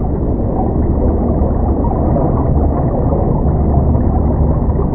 water
underwater.ogg